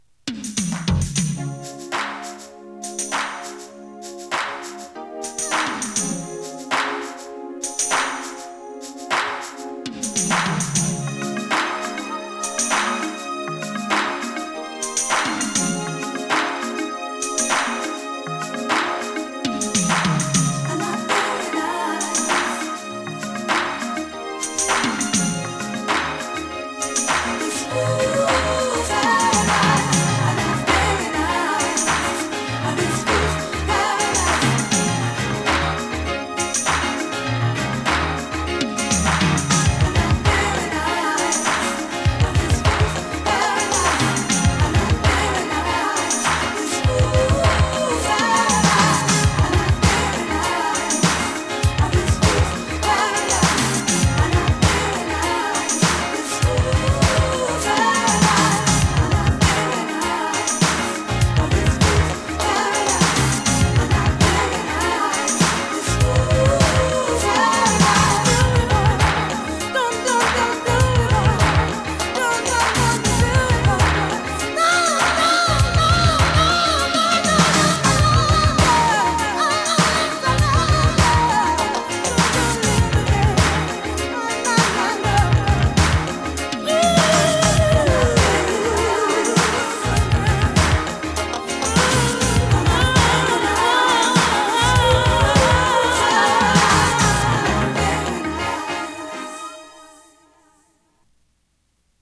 跳ねるピアノとベースが印象的なブラコン・ファンク！